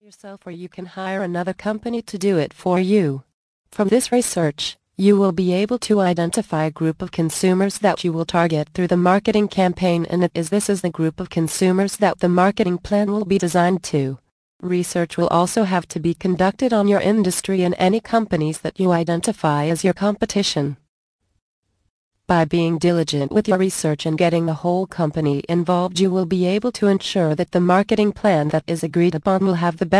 How to Create a Successful Business Plan Audio Book + Gift